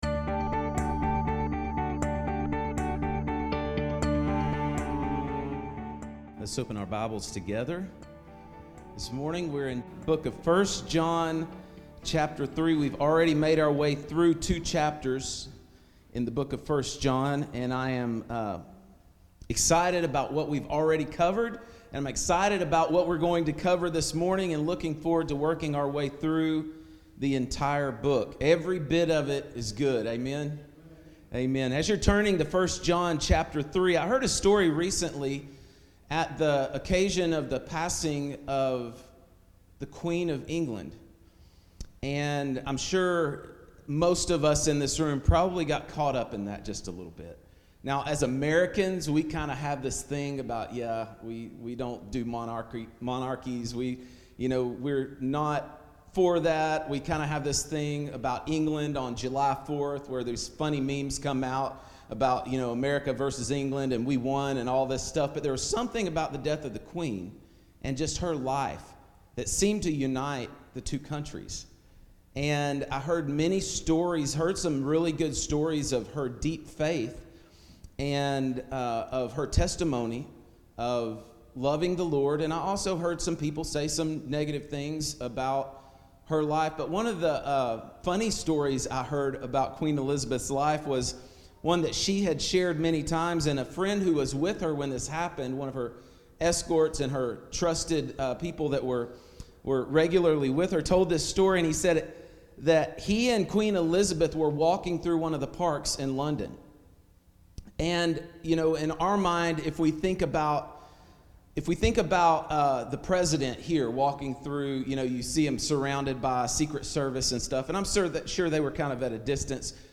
A Sermon Series Through First John